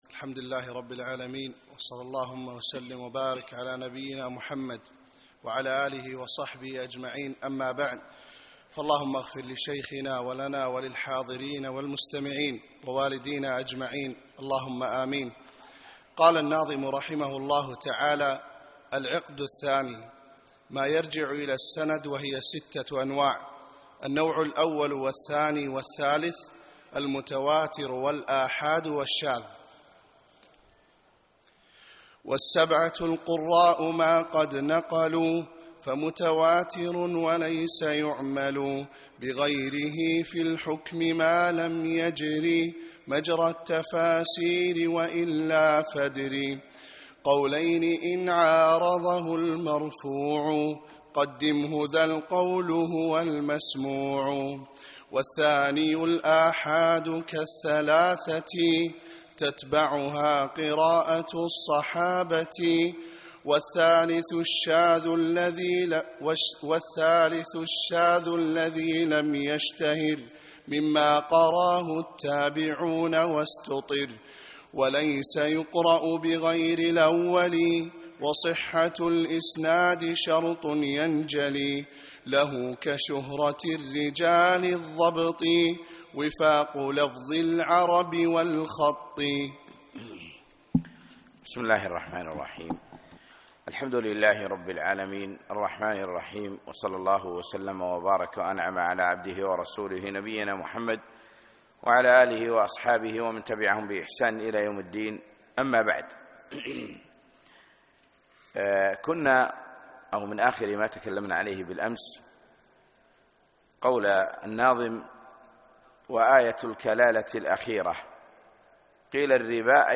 الدرس الثالث : من البيت 46 إلى البيت 66